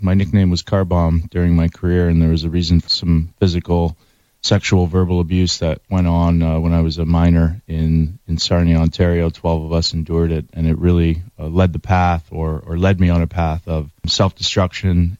On the Morning Buzz on Rock 107, Dan Carcillo says the abuse and mental struggles began in junior hockey.